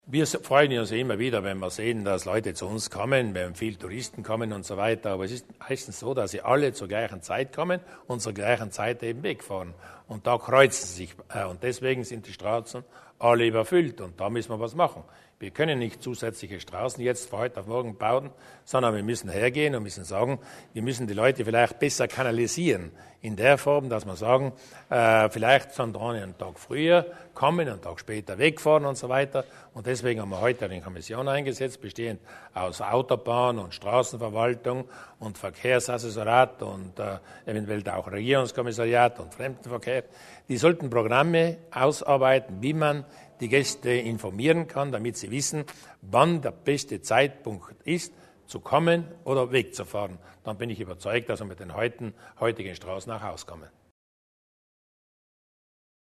Landeshauptmann Durnwalder zum Thema Reiseverkehr auf Südtirols Straßen
Eine Kommission soll nach Lösungen suchen, um das hohe Verkehrsaufkommen an Tagen mit Urlauberschichtwechsel in den Griff zu bekommen. Dies hat Landeshauptmann Luis Durnwalder heute (10. Jänner) im Anschluss an die Sitzung der Landesregierung bekannt gegeben.